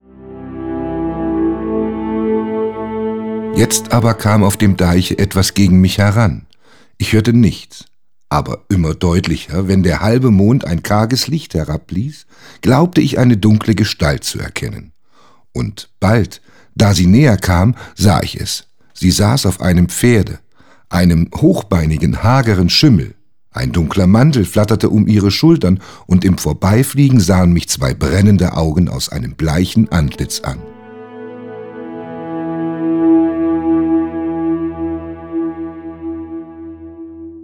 – Theodor Storm: Der Schimmelreiter (Gesprochen:)